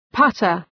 Προφορά
{‘pʌtər}